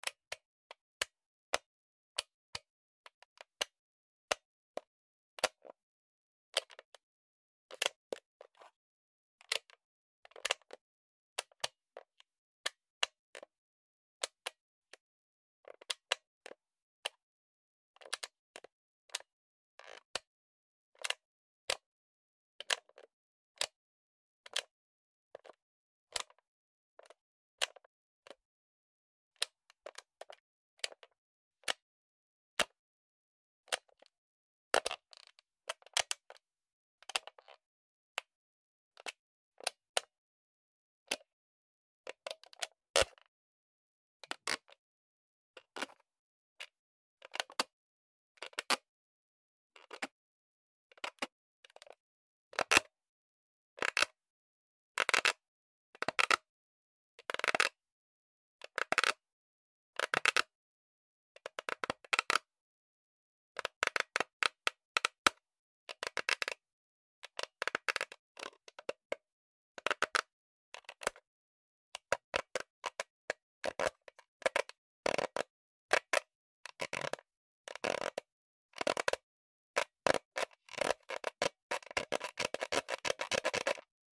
Звуки регулятора громкости
Свитч крутим пальцами для регулировки громкости